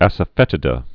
(ăsə-fĕtĭ-də)